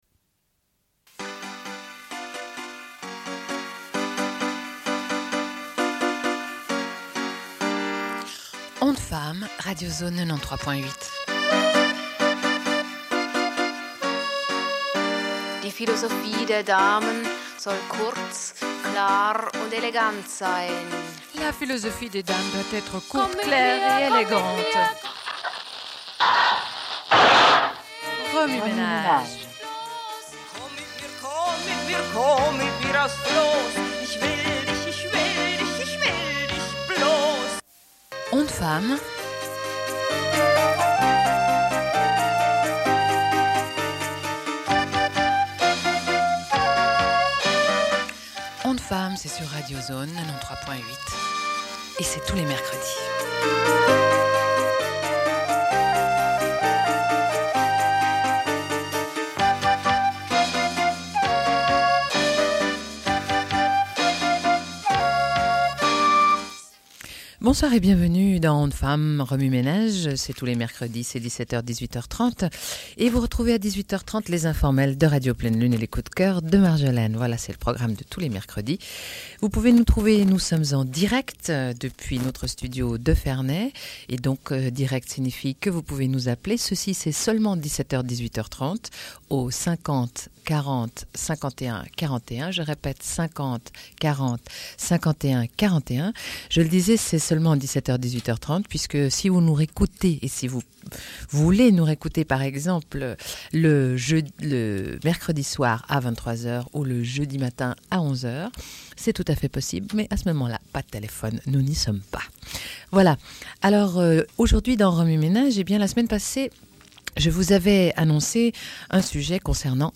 Une cassette audio, face A31:31
Radio Enregistrement sonore